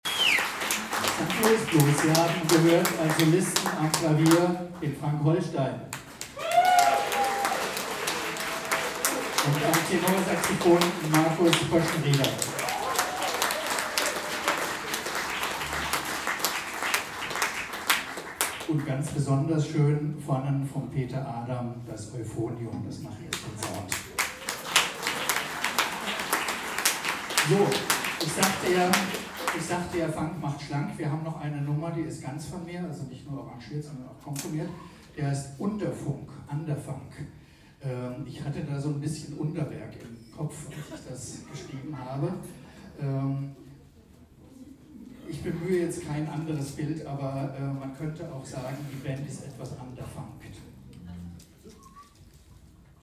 3 03 Ansage [0:53]